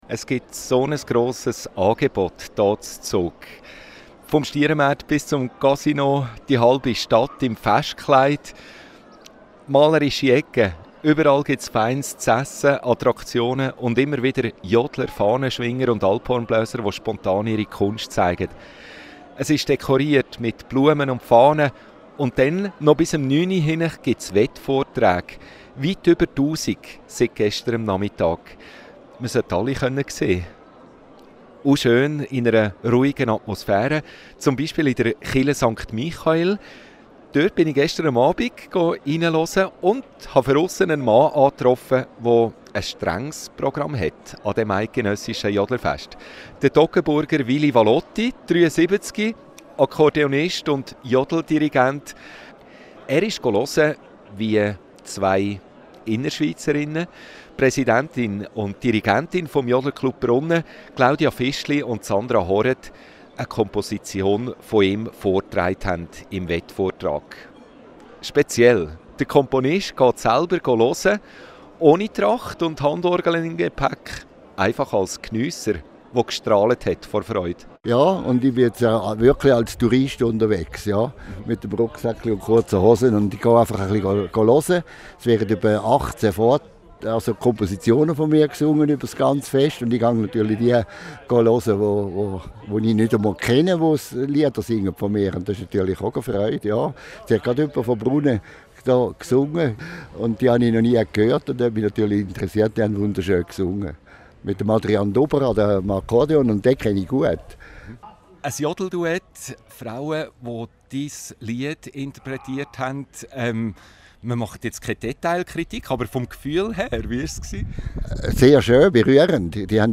Report Radio Central 06/23